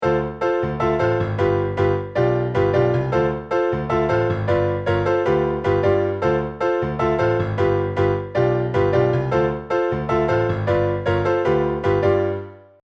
ツー・ファイブを繰り返す曲 とかもあります。
このコード進行かわいいねっ。